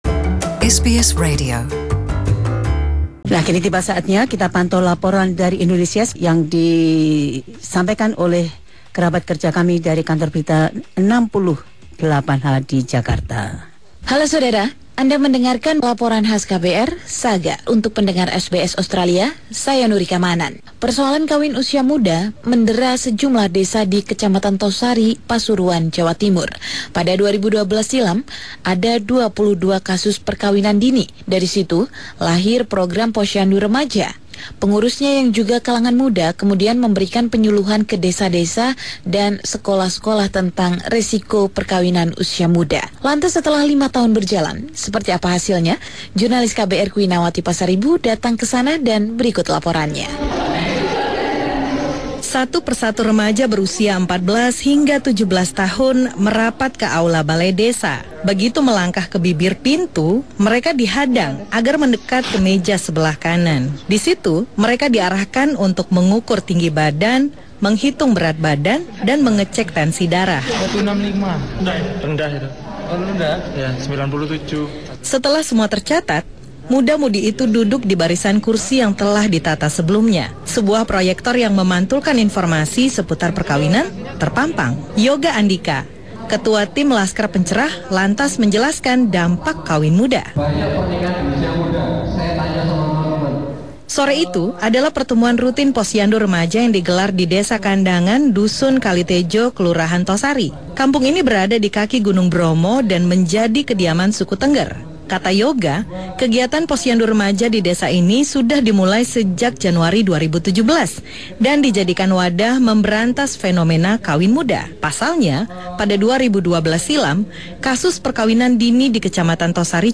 This special report from the team at KBR 68 H describes how young Indonesians are helping to increase other young Indonesians’ reproductive health.